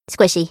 squishy